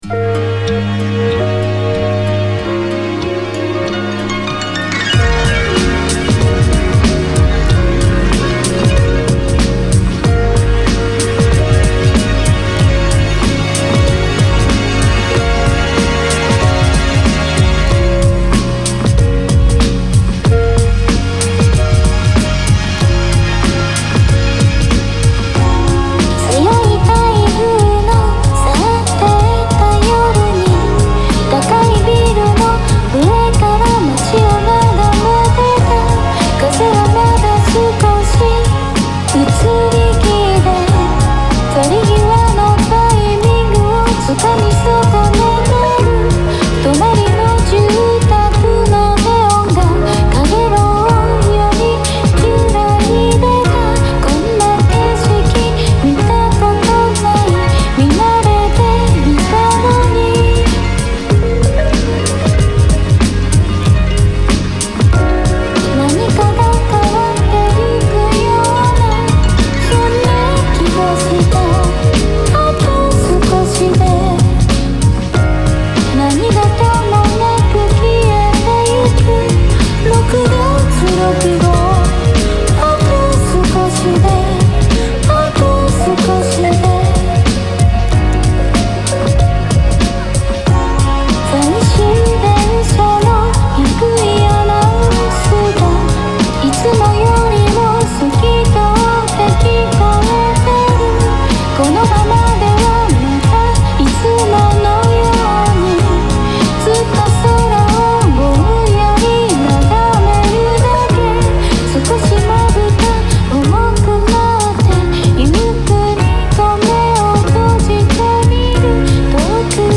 レコードの温かみやノイズを加えたり、
真空管を通したアナログ感を加えてみたり
レコード音源からビートを作ったりで
どうにか郷愁的なサウンドを追い求めてみました。